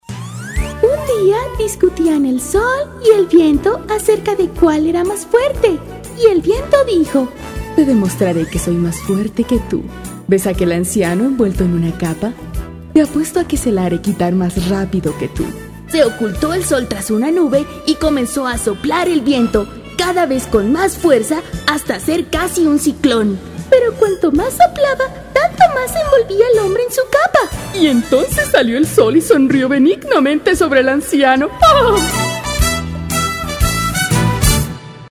Poseo una voz versátil para locución publicitaria y doblaje. Mi rango de voz abarca desde niños y niñas pequeños hasta mujeres de mediana edad.
Sprechprobe: Sonstiges (Muttersprache):